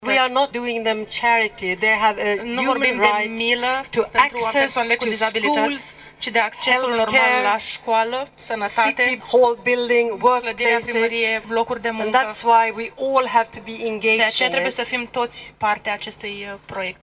Cu acest prilej, diplomatul suedez a subliniat că şi în România trebuie construită o societate incluzivă.
3-dec-rdj-20-Ambasador-Suedia.mp3